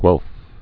(gwĕlf)